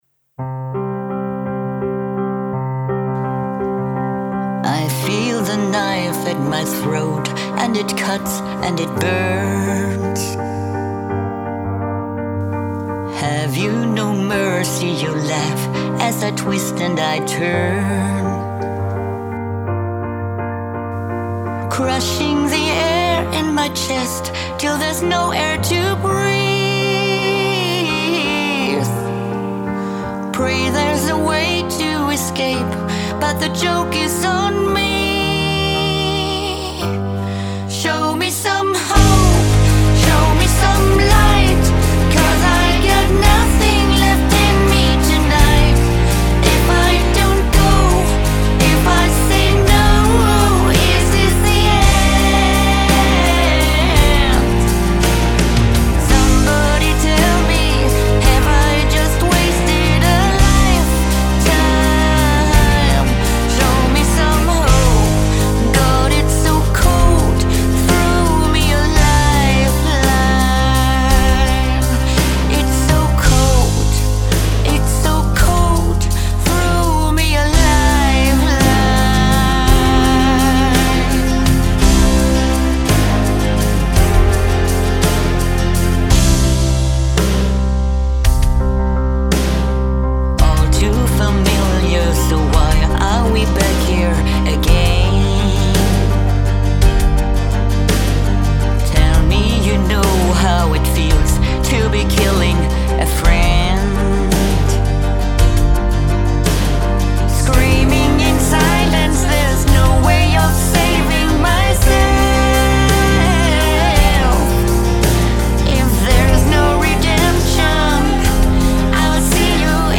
Während ich mit den Melodien und Beats zufrieden bin, bin ich mit den Stimmen noch nicht ganz glücklich.
Die Aufnahmequalität der Vocals ist net so....und ne Menge Töne off.